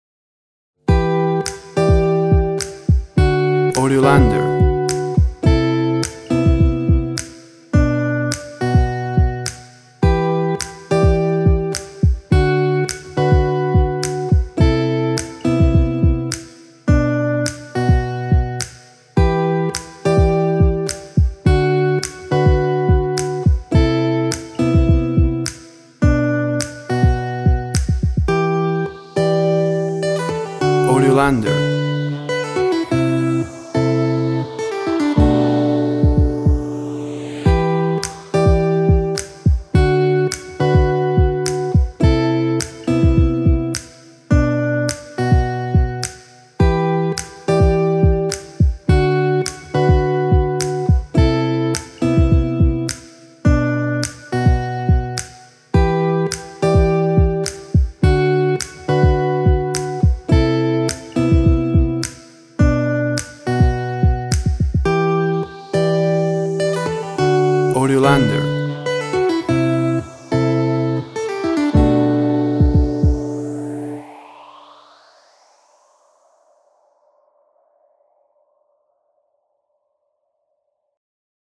Music For Relax, Guitar And Drum Machine.
Tempo (BPM) 105